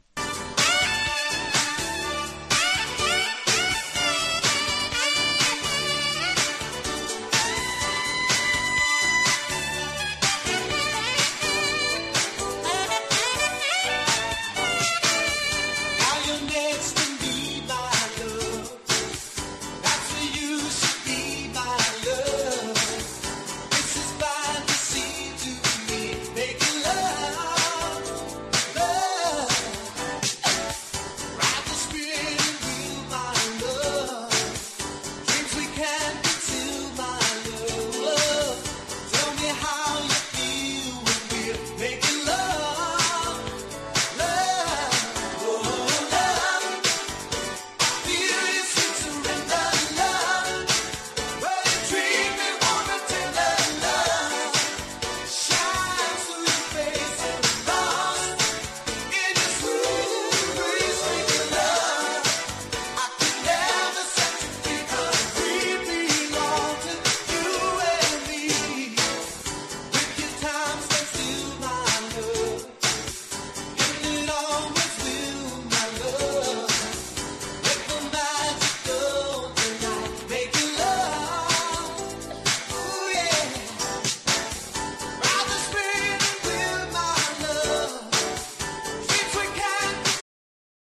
AORからブラコン好きまで人気の１枚。